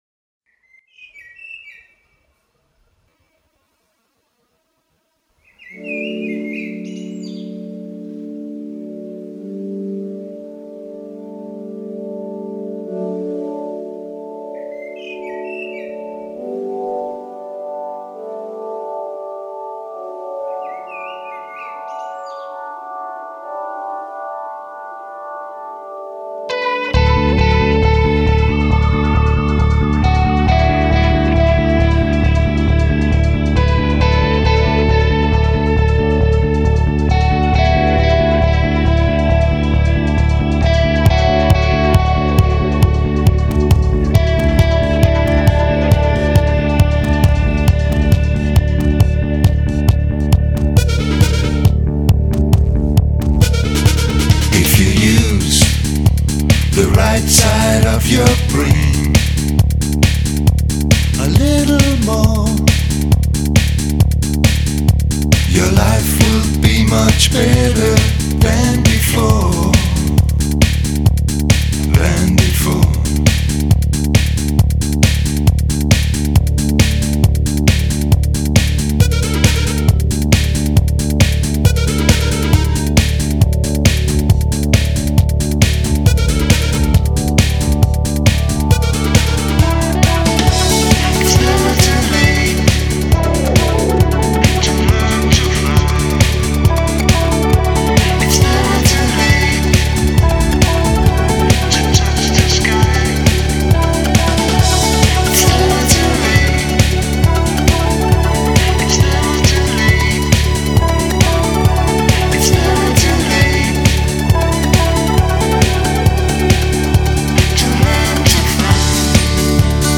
Диско, поп-музыка